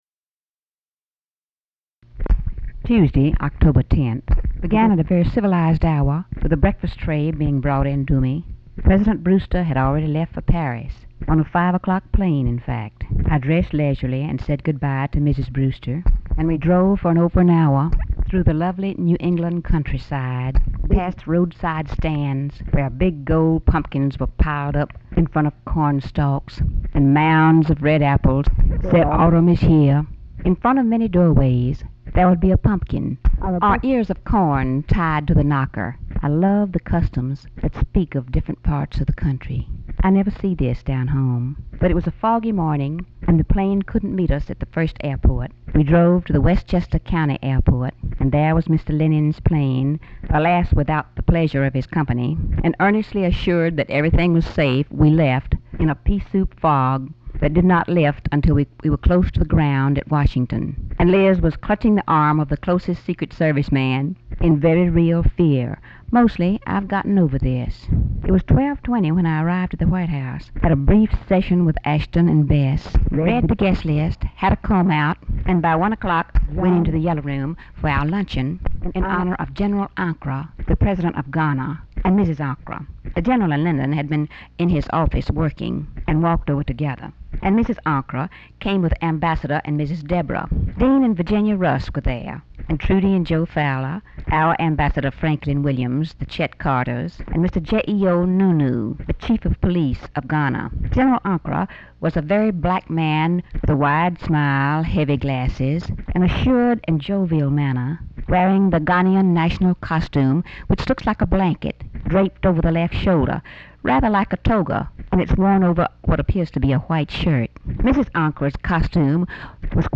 Audio diary and annotated transcript, Lady Bird Johnson, 10/10/1967 (Tuesday) | Discover LBJ